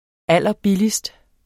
Udtale [ ˈalˀʌˈbilisd ]